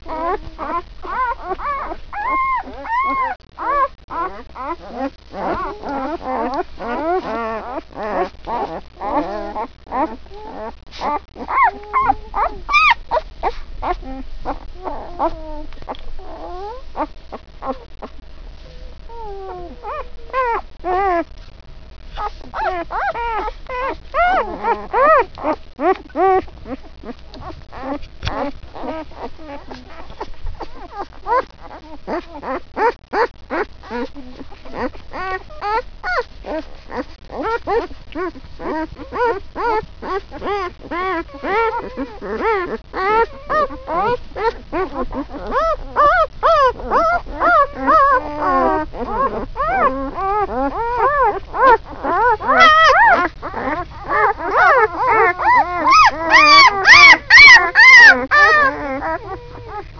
Click here to hear Comet talk.
puptalk.wav